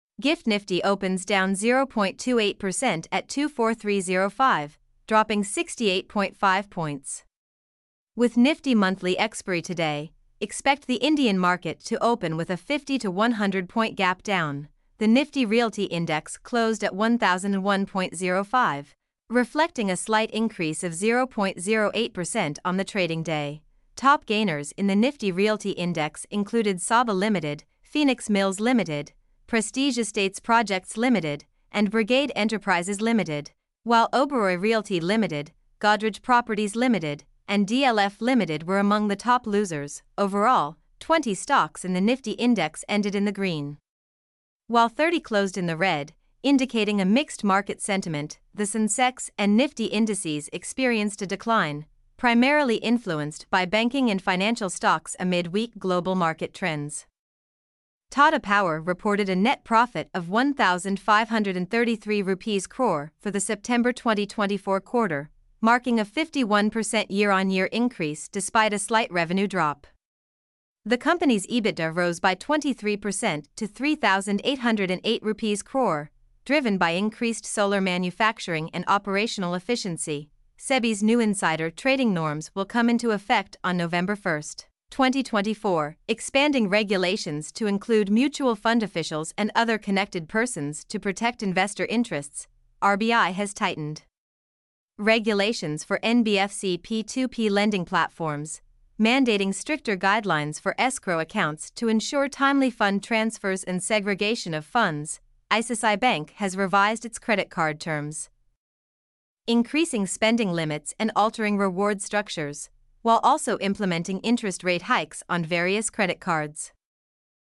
mp3-output-ttsfreedotcom-20.mp3